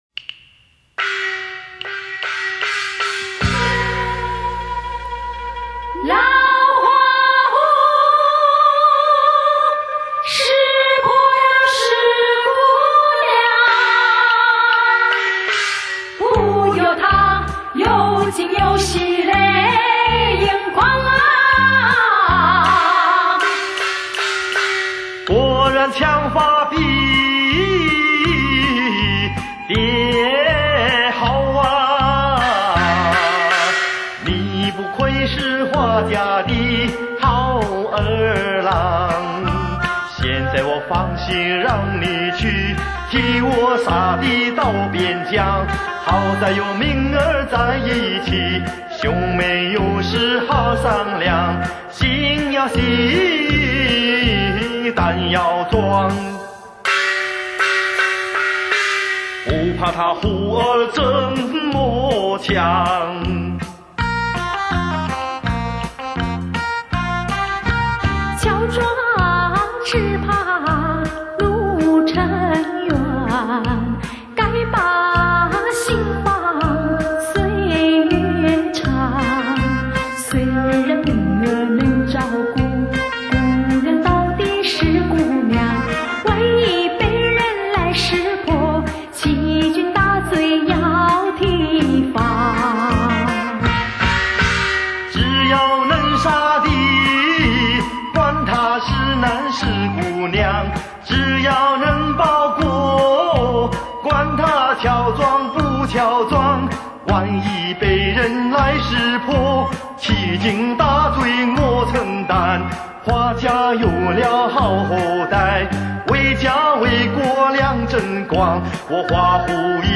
[mjh4][light]那歌声...那旋律...悠扬飘荡...[/light][/mjh4]